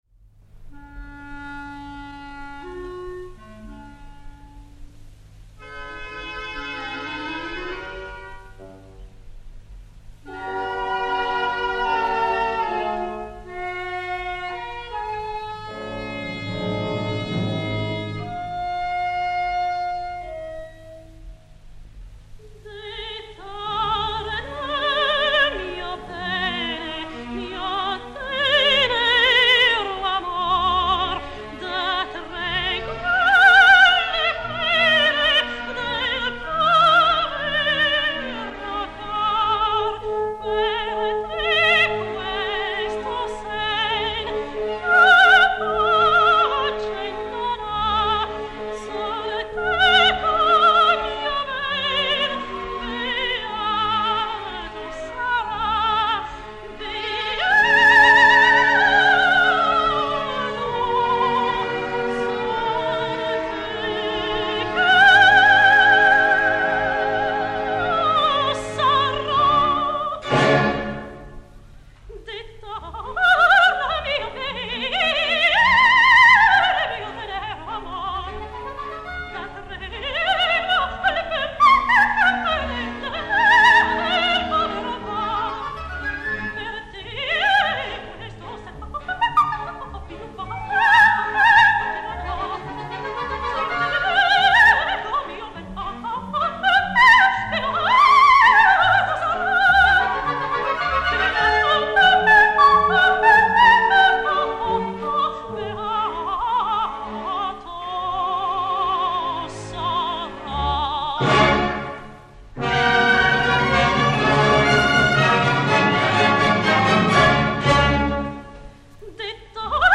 flûte solo